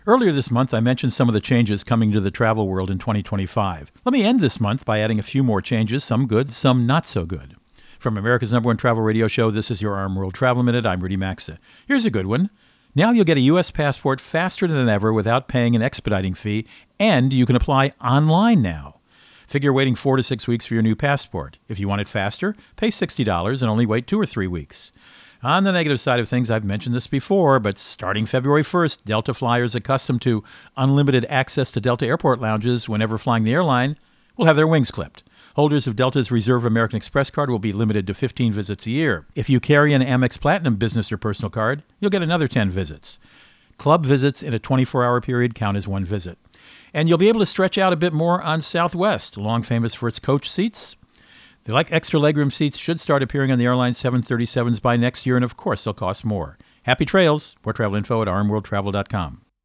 Co-Host Rudy Maxa | Changes in Travel are Coming as 2025 Unfolds